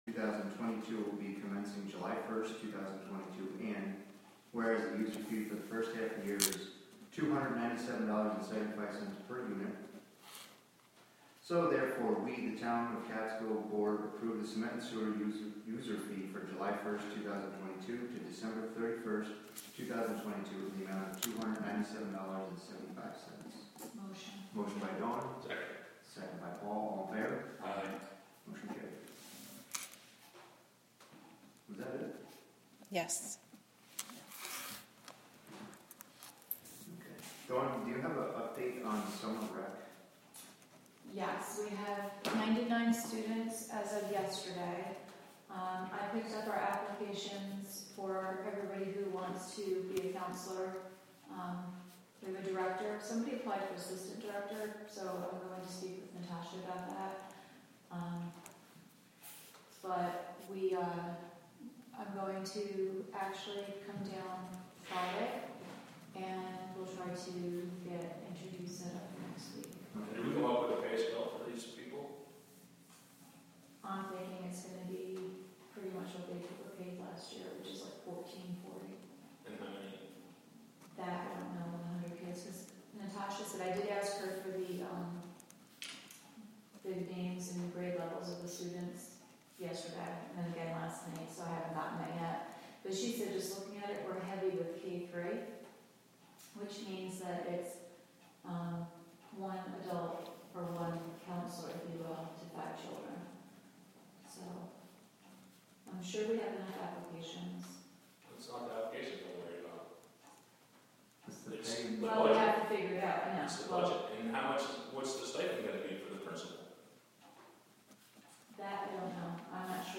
Live from the Town of Catskill: June 15, 2022 Town Board Meeting (Audio)